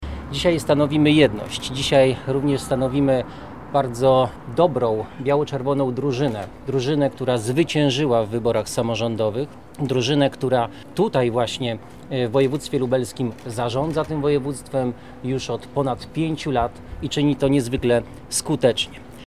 W piątek (21 czerwca) na Placu Teatralnym odbyła się konferencja prasowa z udziałem posła Przemysława Czarnka, marszałka Jarosława Stawiarskiego oraz pozostałych członków zarządu. Tematem spotkania było wyjaśnienie informacji o mniemanych zmianach w składzie Zarządu Województwa Lubelskiego oraz braku porozumienia.
Sylwester Tułajew – podkreślił poseł Sylwester Tułajew.